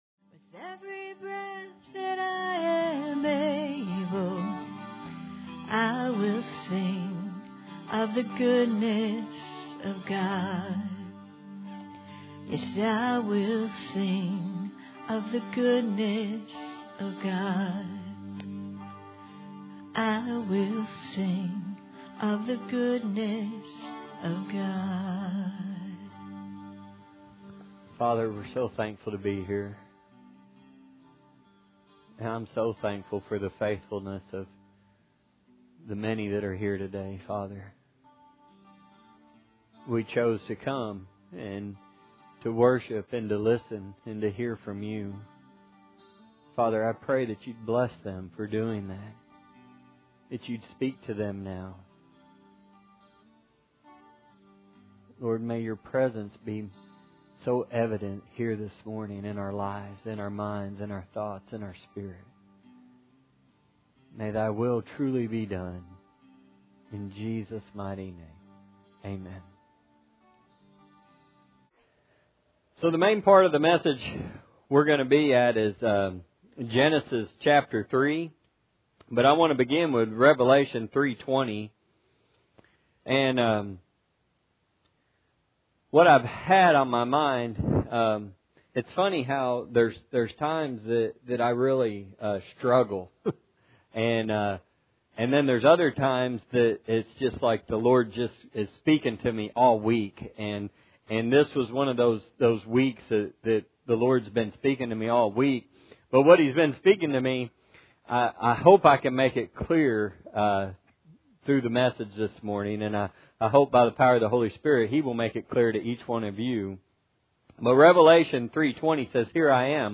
Matthew 4:4 Service Type: Sunday Morning Audio Version Below